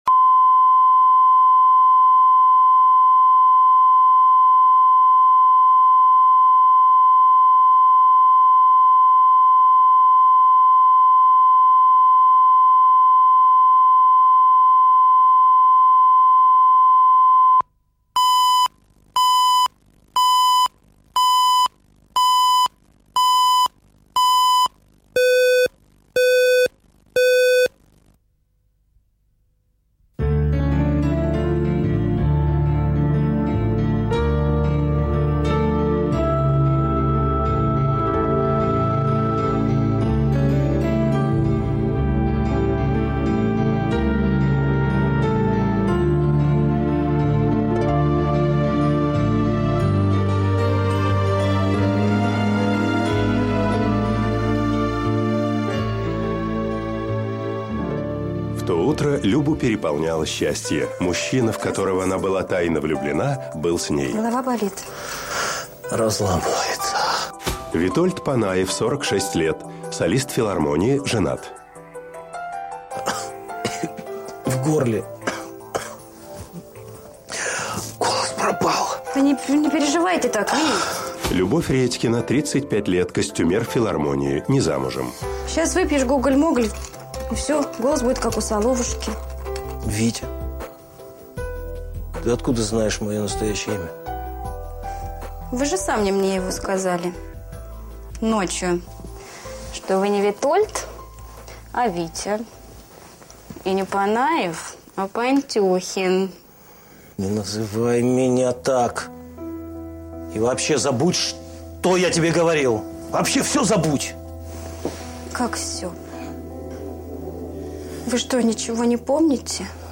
Аудиокнига Мезальянс | Библиотека аудиокниг